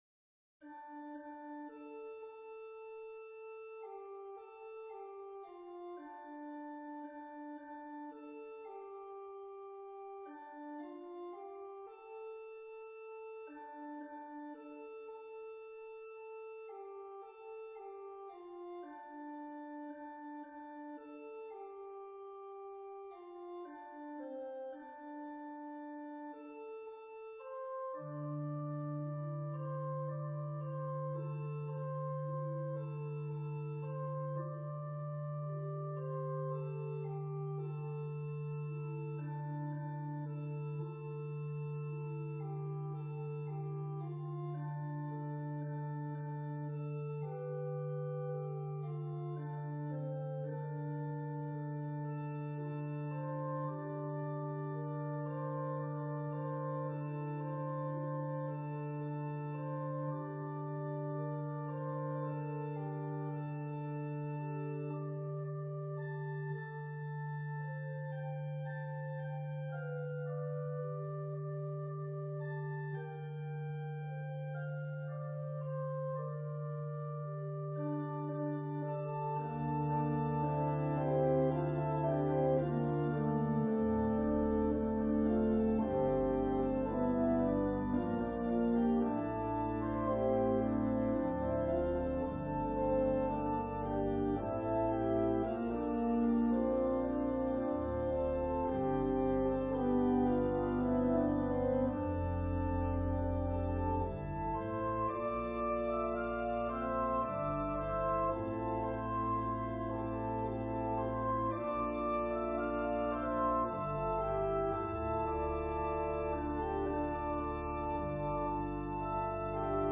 An organ solo version of Mack Wilberg's choral arrangement.
Voicing/Instrumentation: Organ/Organ Accompaniment We also have other 40 arrangements of " A Poor Wayfaring Man of Grief ".